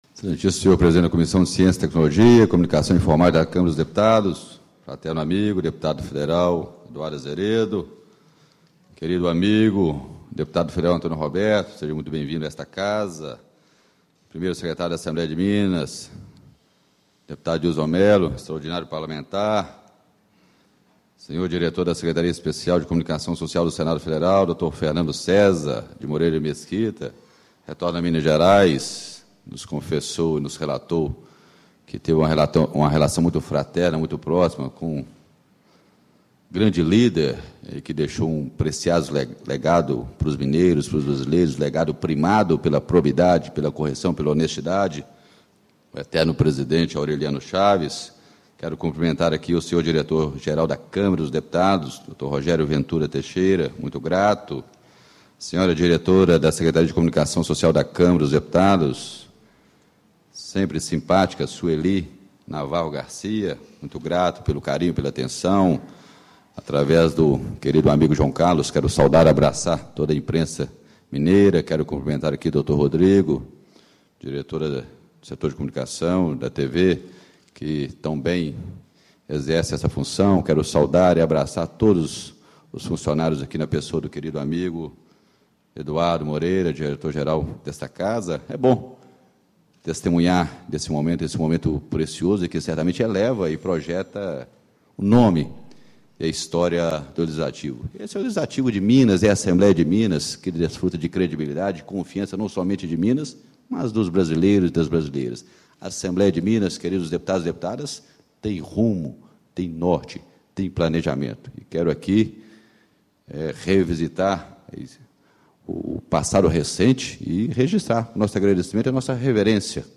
Deputado Dinis Pinheiro, PSDB, Presidente da Assembleia Legislativa do Estado de Minas Gerais.
Solenidade de Lançamento do Canal Digital 61 da Rede Legislativa de TV Digital
Discursos e Palestras